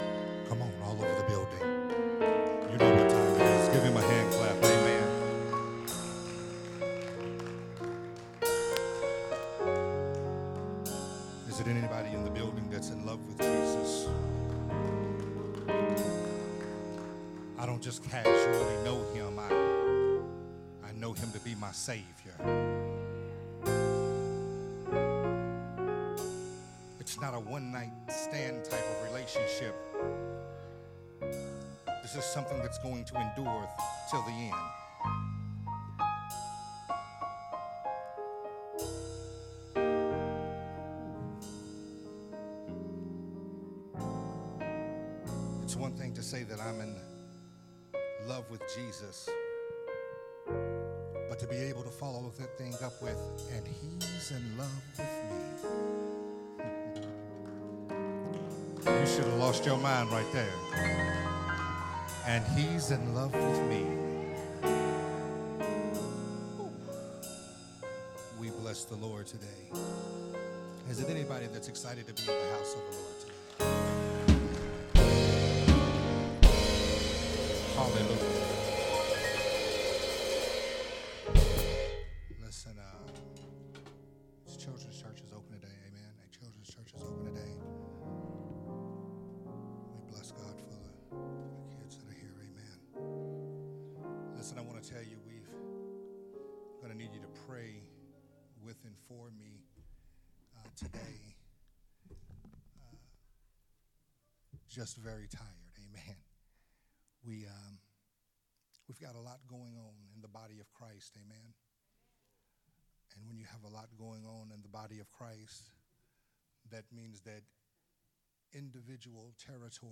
a Sunday Morning Worship service message
recorded at Unity Worship Center on June 25, 2023.